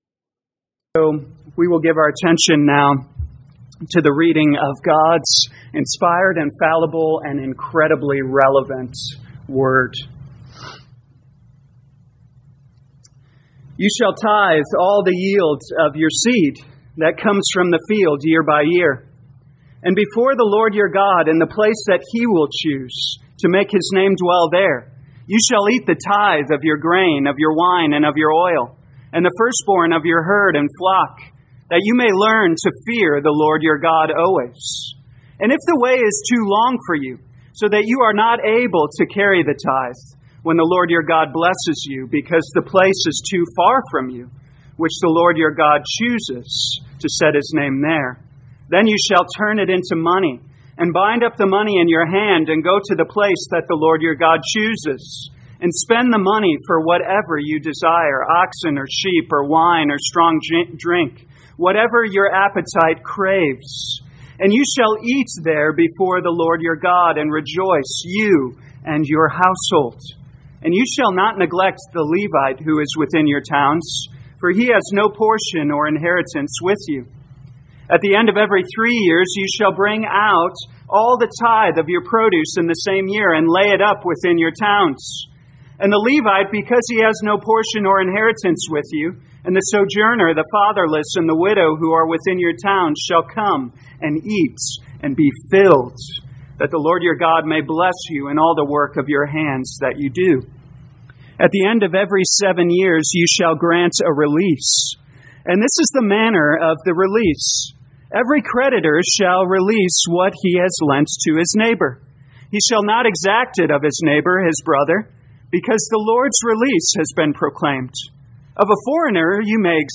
2022 Deuteronomy The Law Evening Service Download